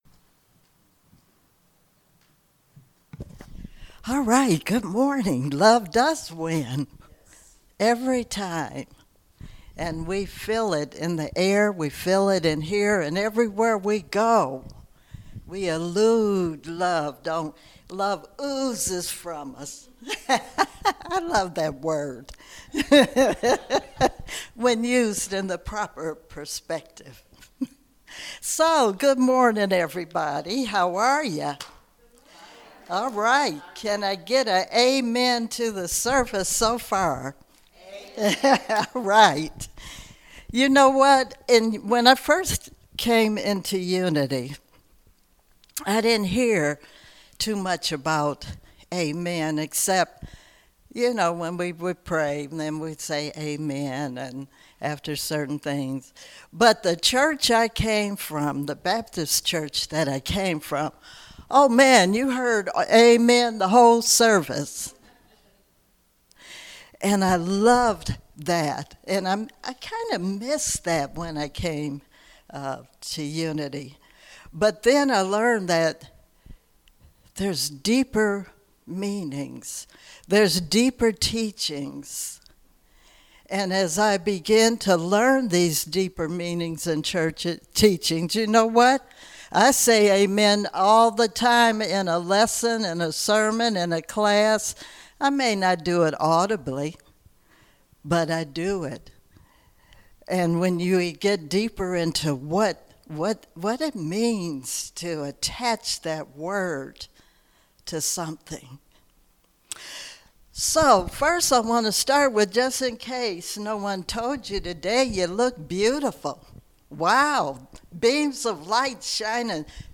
Spiritual Leader Series: Sermons 2021 Date